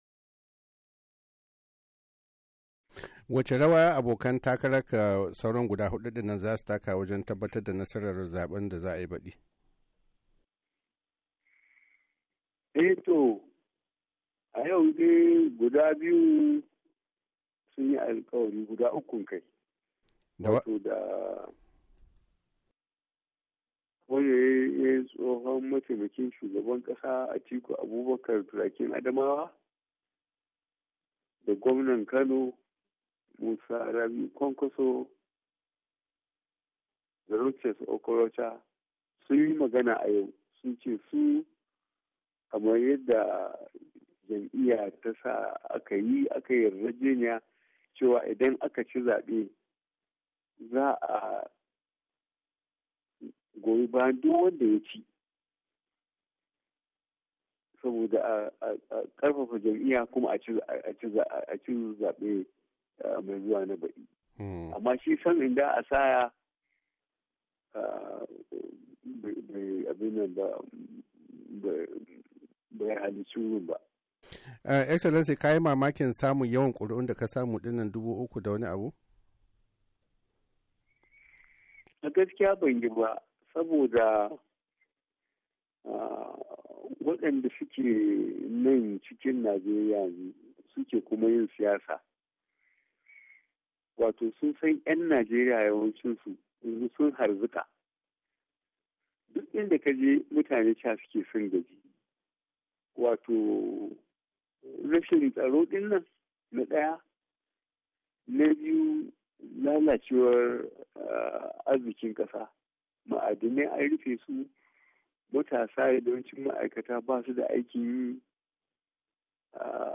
Sashen Hausa na Muryar Amurka ya tattauna da Janar Muhammadu Buhari, tsohon shugaban kasa kuma dan takarar jam'iyyar APC mai adawa game da nasarar da yayi, da kuma batutuwan da jama'a yanzu suke muradin sani.